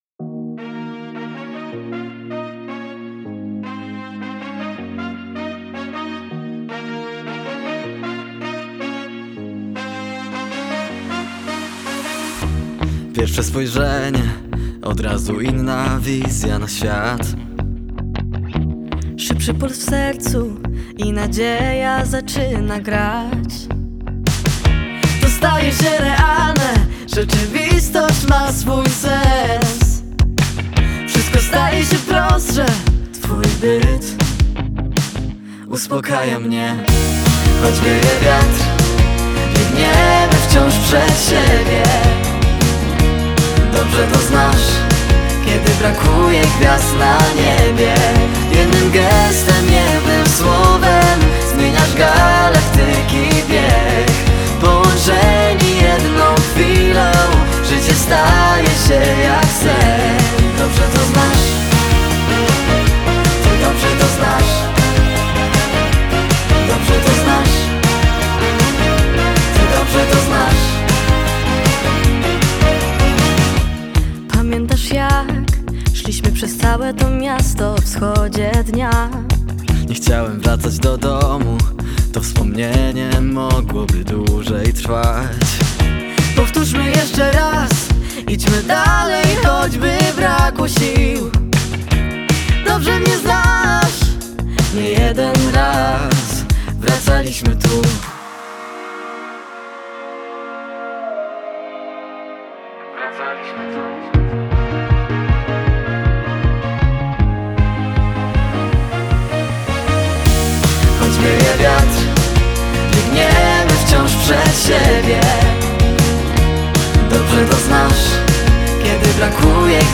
radiowy pop duet